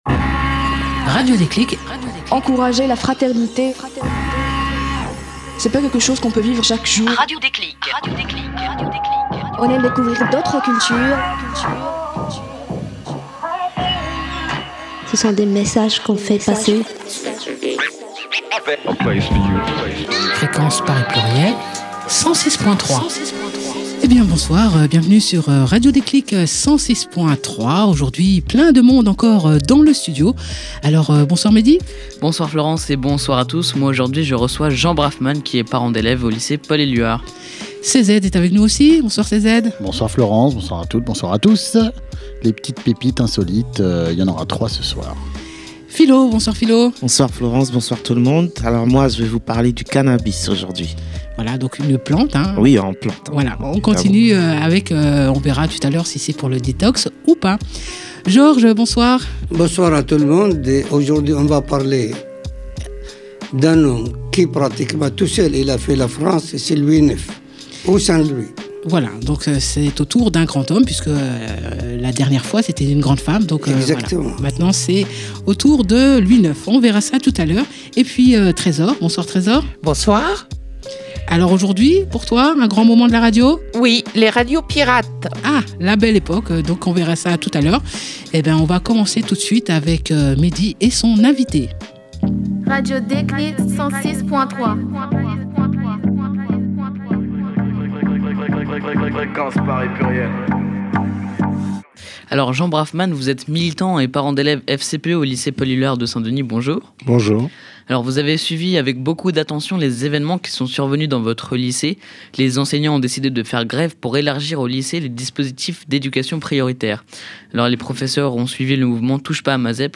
Au sommaire de l’émission diffusée sur les ondes de FPP 106.3fm et en podcast sur notre site